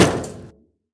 metal_place_1.ogg